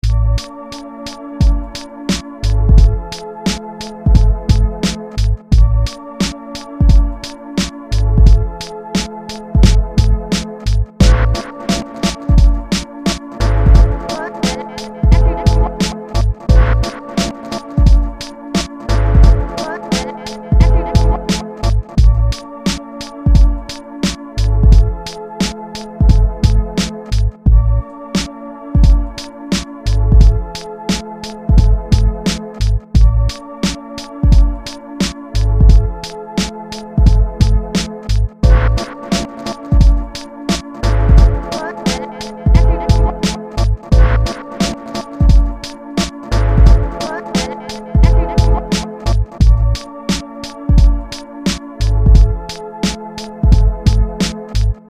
soulful